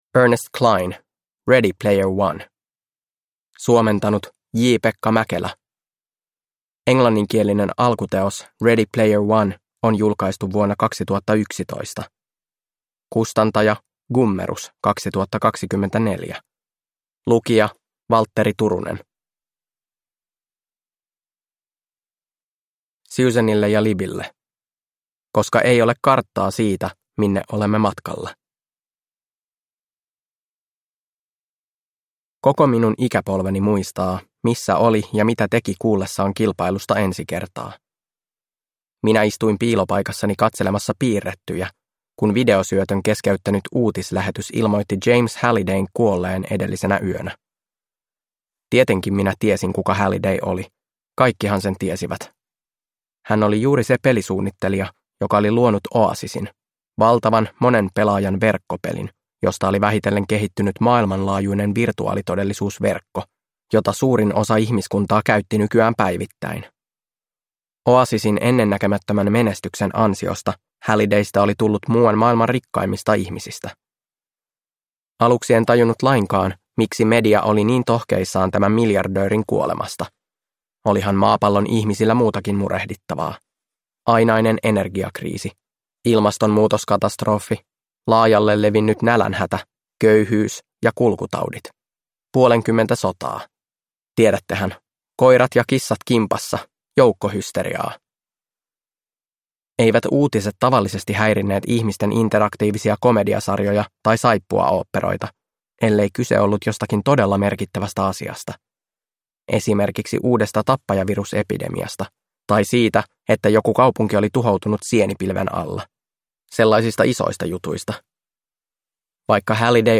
Ready Player One – Ljudbok